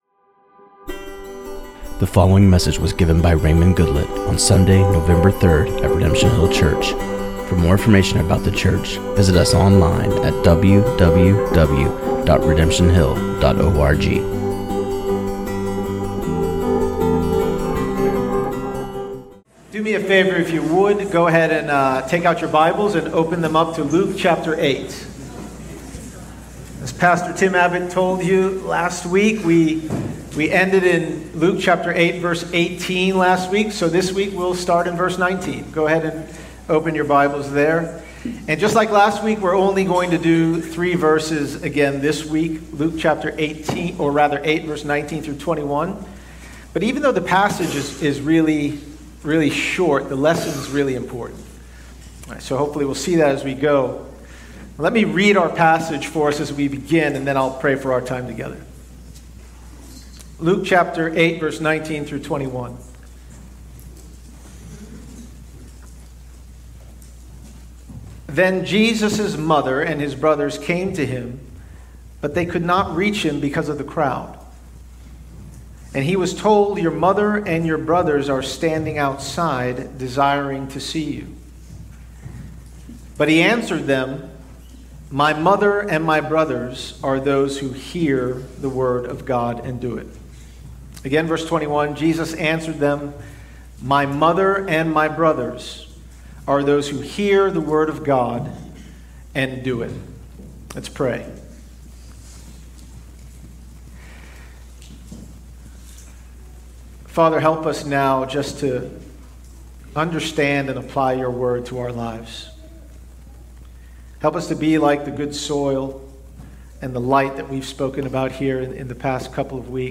This sermon on Luke 8:19-21